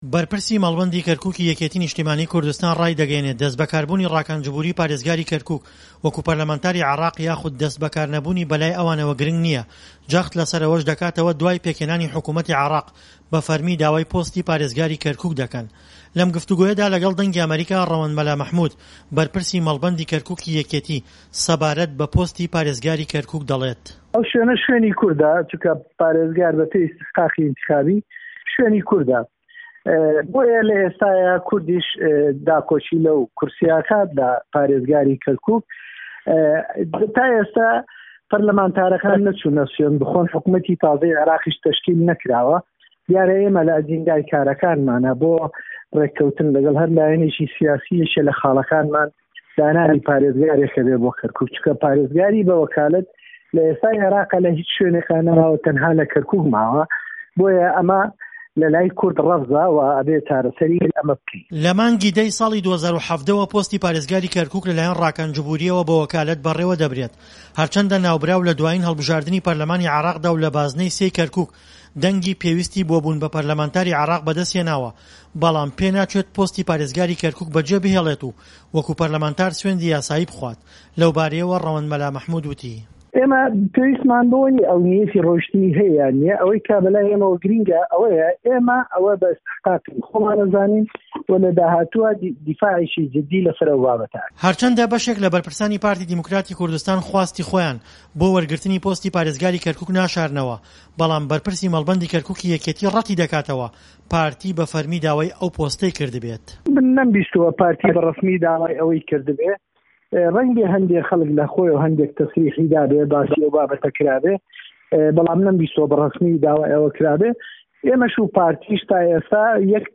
ڕاپۆرتی پەیامنێر